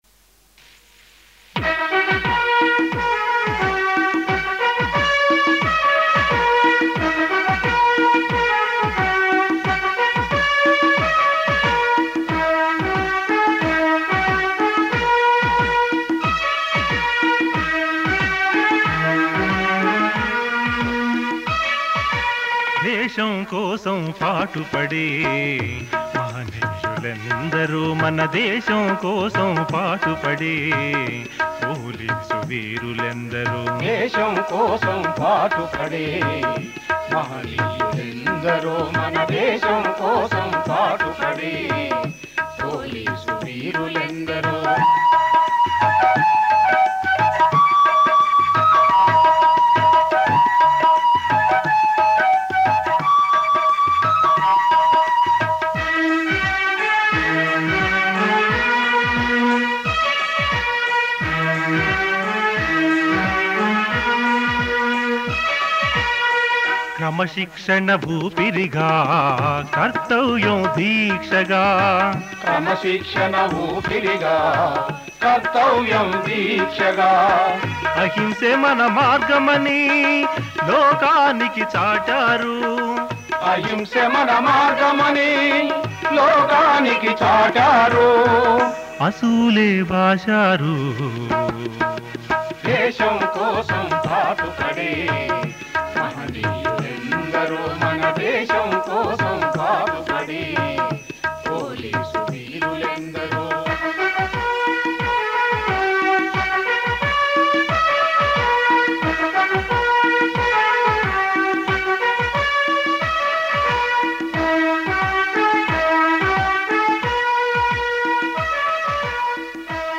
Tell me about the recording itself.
Several songs were written, recorded and performed among the people.